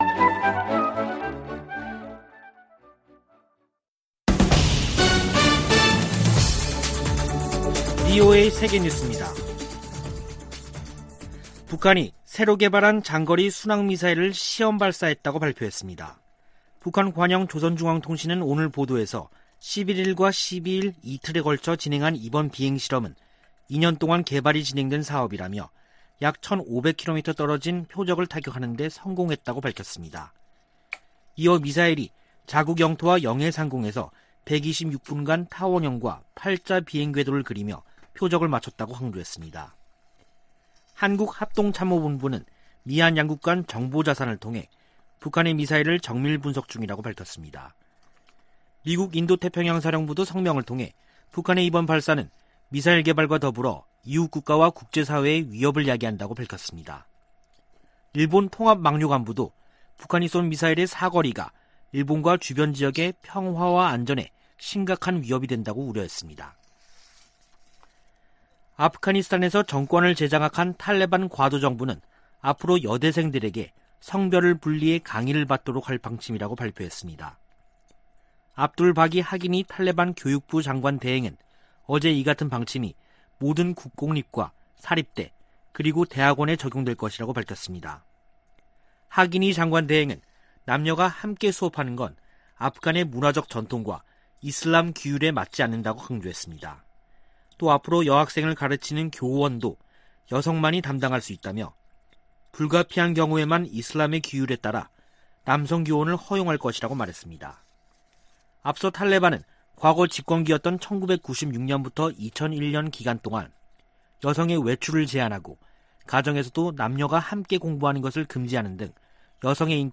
VOA 한국어 간판 뉴스 프로그램 '뉴스 투데이', 2021년 9월 13일 3부 방송입니다. 북한이 미-한 연합훈련 반발 담화를 낸 지 한 달 만에 신형 장거리 순항미사일을 시험발사했다고 밝혔습니다. 미 인도태평양사령부는 북한의 미사일 발사에 관해 계속 상황을 주시할 것이고, 동맹ㆍ협력국들과 긴밀히 협의하고 있다고 밝혔습니다. 미국 전문가들은 한국이 개발하는 잠수함들이 북한을 억제하는데 도움이 될 것이라고 전망했습니다.